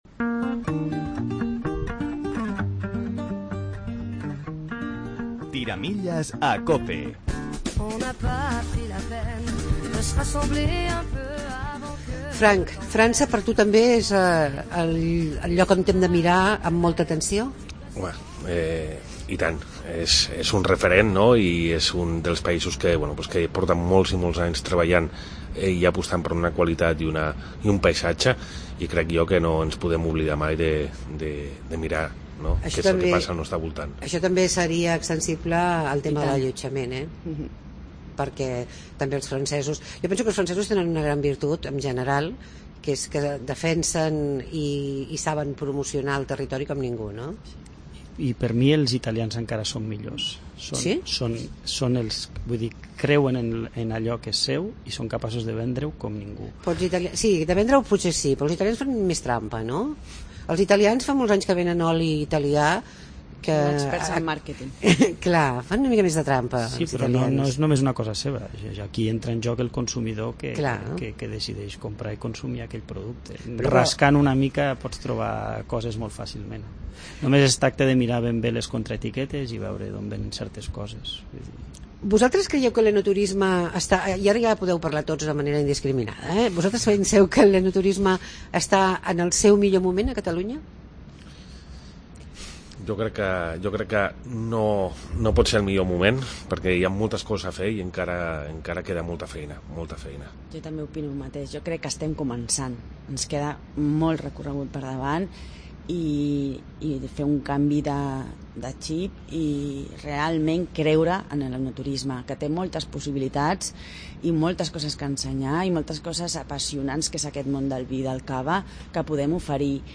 ESPECIAL ENOTURISME TiraMillesCOPE des de l'Heretat Oller del Mas.